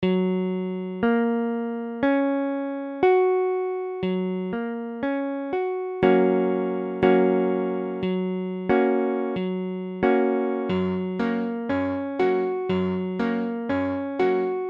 Tablature Gb.abcGb : accord de Sol bémol majeur
Mesure : 4/4
Tempo : 1/4=60
A la guitare, on réalise souvent les accords en plaçant la tierce à l'octave.
Sol bémol majeur barré II (sol bémol case 2 ré bémol case 4 doigt 3 sol bémol case 4 doigt 4 si bémol case 3 doigt 2 ré bémol case 2 sol bémol case 2)